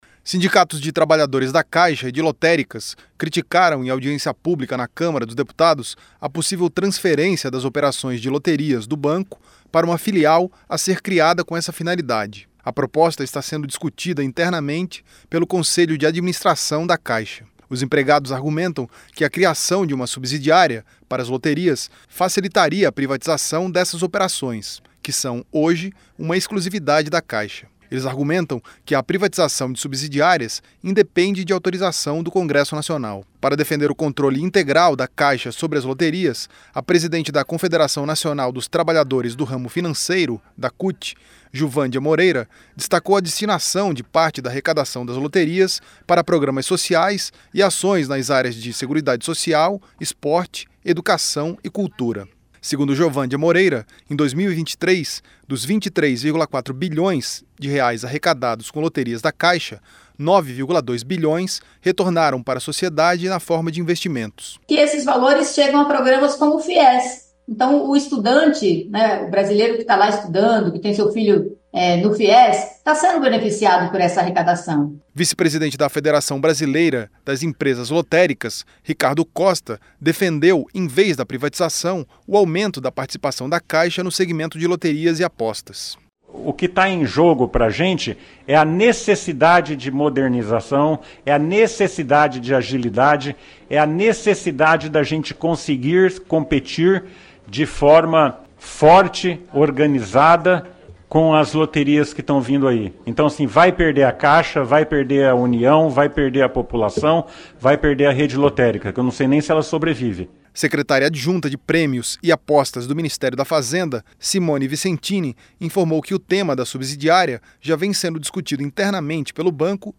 Representantes de trabalhadores da Caixa e de empresas lotéricas criticaram nesta quarta-feira (03), em audiência pública na Câmara dos Deputados, a possível transferência das operações de loterias do banco para uma filial a ser criada com essa finalidade.